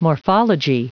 Prononciation du mot morphology en anglais (fichier audio)
Prononciation du mot : morphology